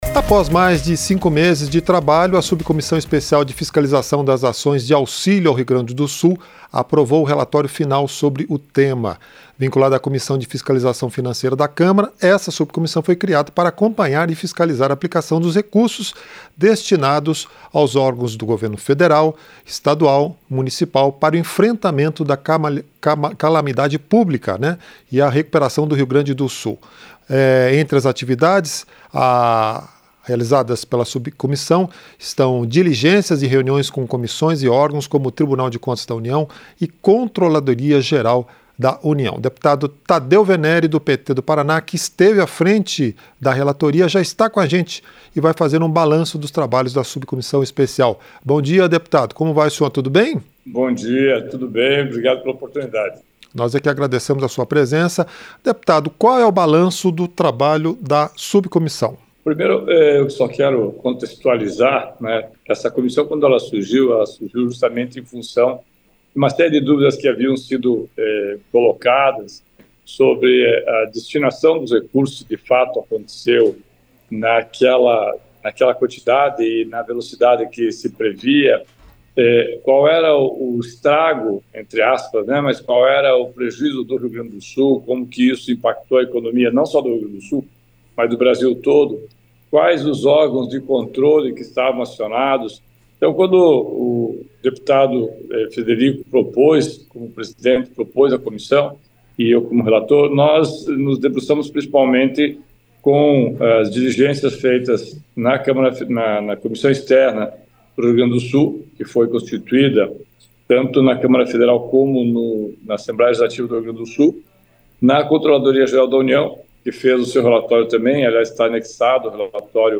Entrevista - Dep. Tadeu Veneri (PT-PR)